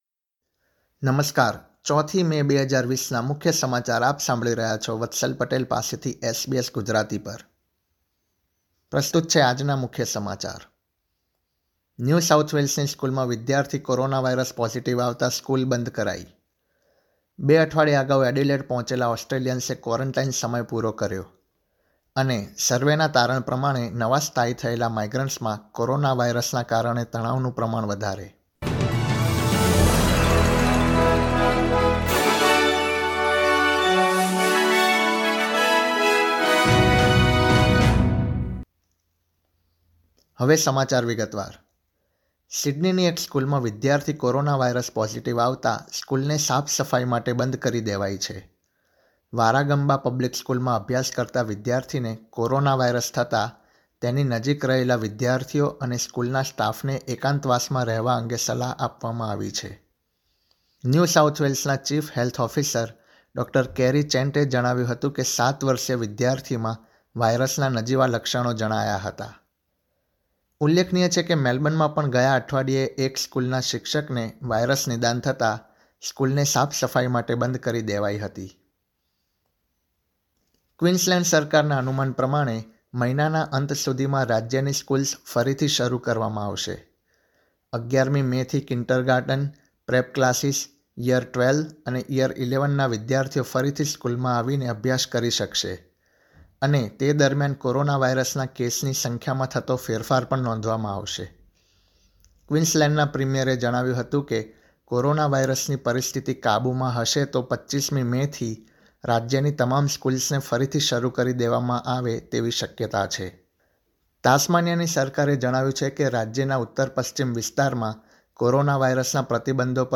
final_new_1_news_bulletin_0405.mp3